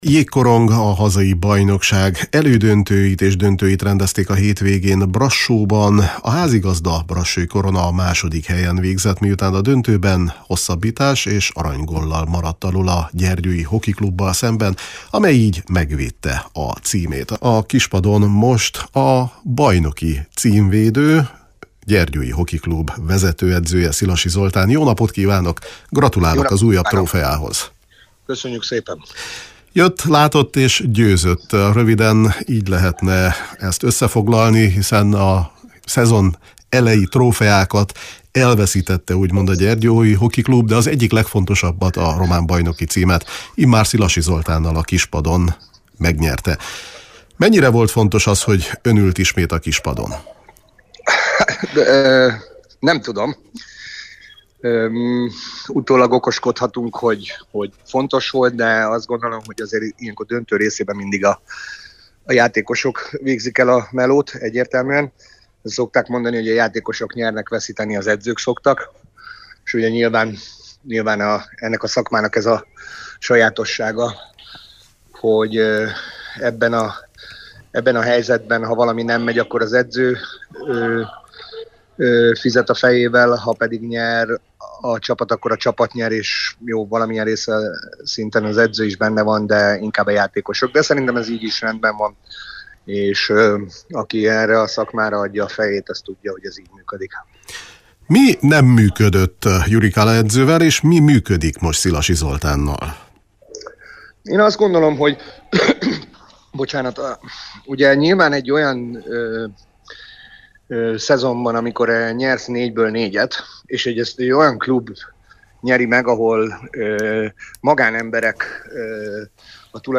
A Kispad sportműsorunkban a dobogós csapatok képviselőivel értékeltünk